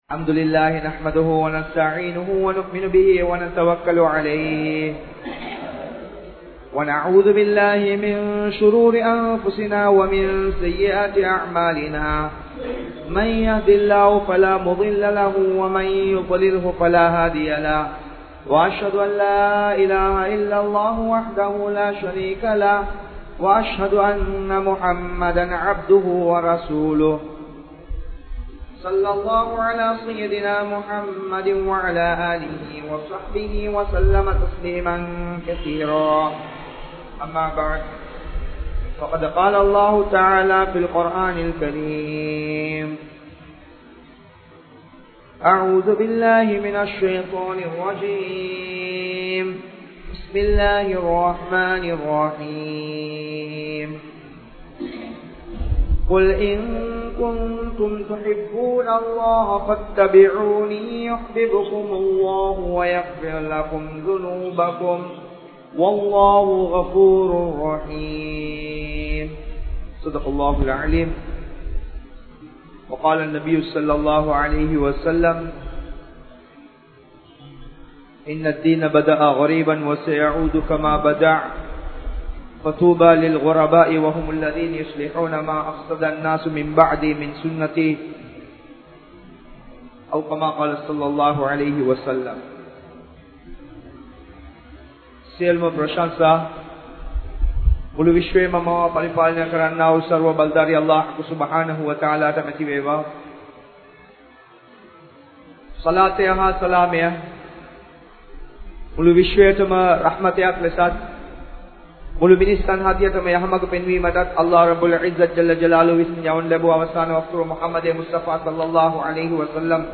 Sunnahvai Nadia Muraipaduthuvoam (ஸூன்னாவை நடைமுறைப்படுத்துவோம்) | Audio Bayans | All Ceylon Muslim Youth Community | Addalaichenai
Ar Rahmath Jumua Masjidh